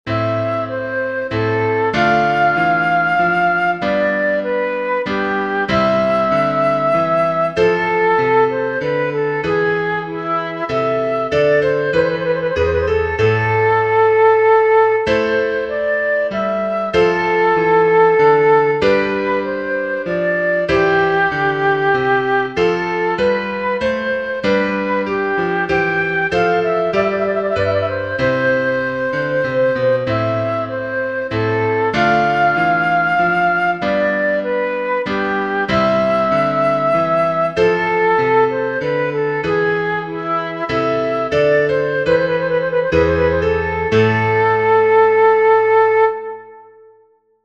Andante
Hangkészlet : É’–G”
Ütemmutató: 3/4 Tempo: 96 bpm
Előadói apparátus: fuvola + zongora